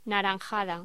Locución: Naranjada